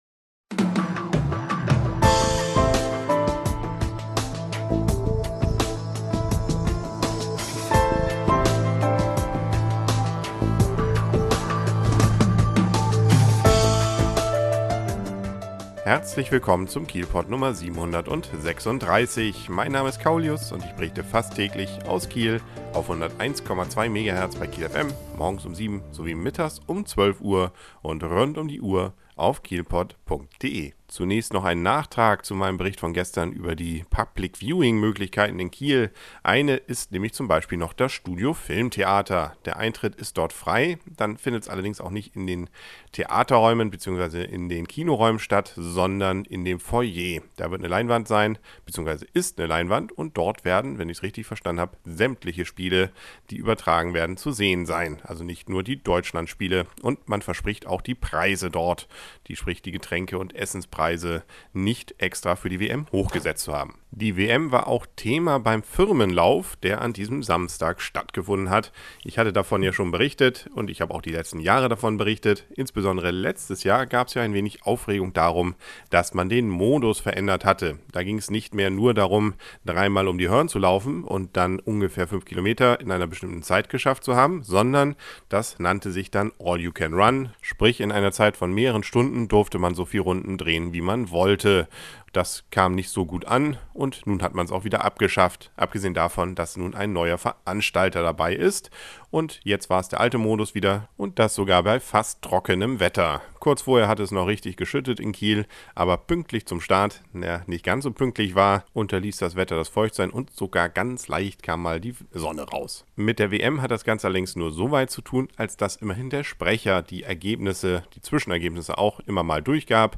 An diesem Samstag fand wieder ein Firmenlauf rund um die Hörn statt. Ich konnte einige Interviews hierzu führen.